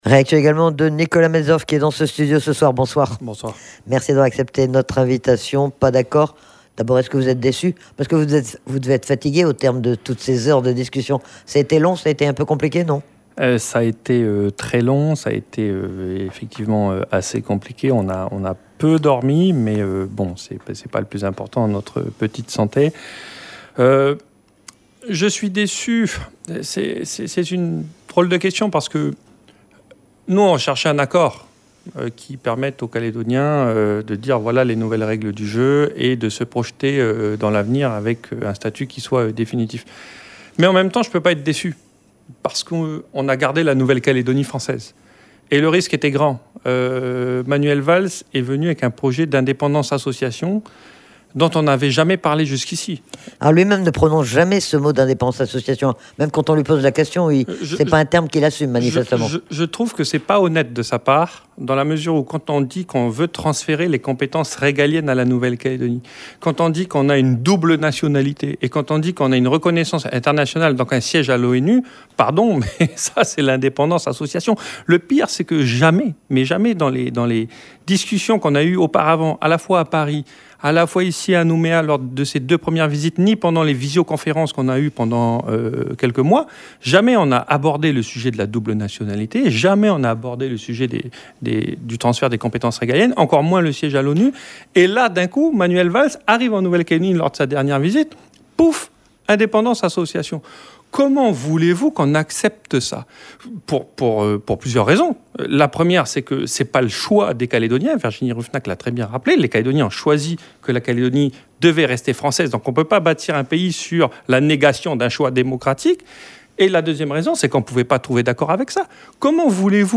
a justement reçu le député de la 1ère circonscription Nicolas Metzdorf